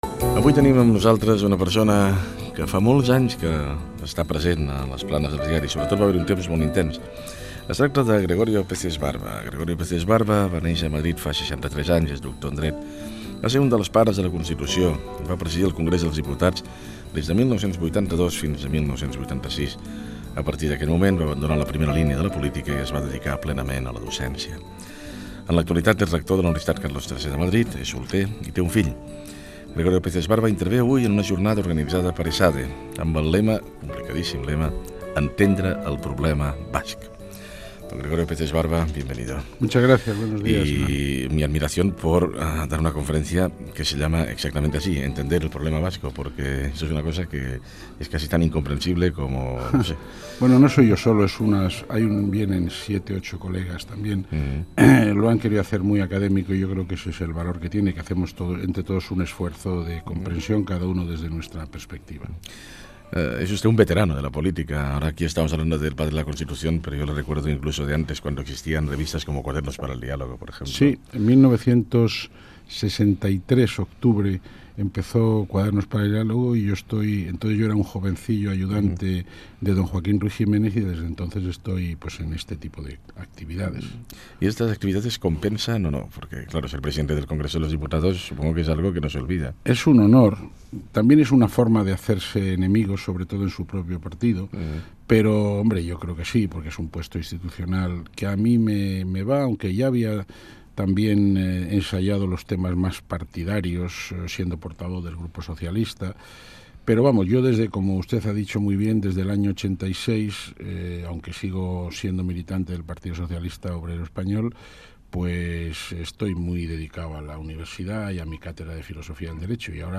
Fragment d'una entrevista al polític, pare de la Constitució espanyola i docent, Gregorio Peces Barba.
Info-entreteniment